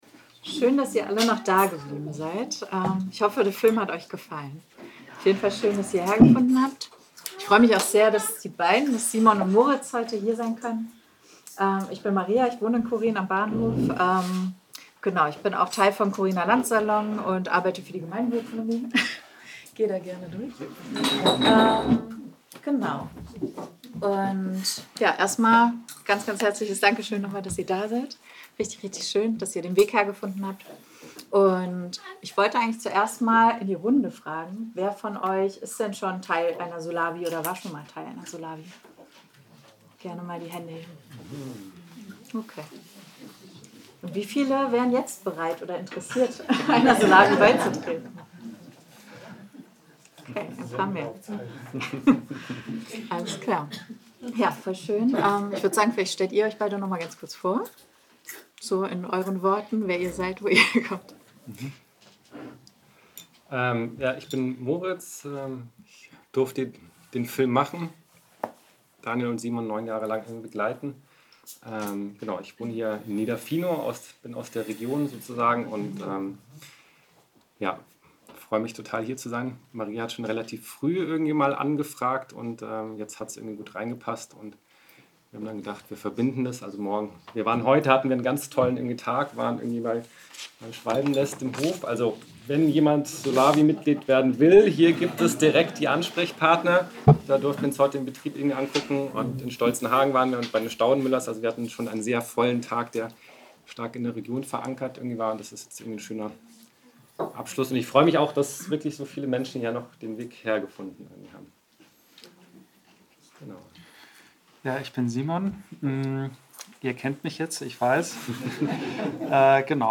Publikumsgespräch
Dieser Film wird gezeigt im Rahmen der "Ökofilmtour".